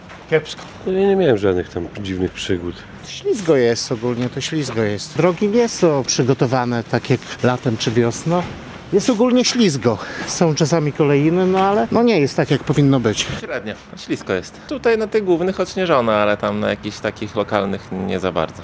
Jak obecnie wygląda sytuacja na lokalnych drogach? Większość zapytanych przez nas kierowców jest zdania, że mogłaby być lepsza.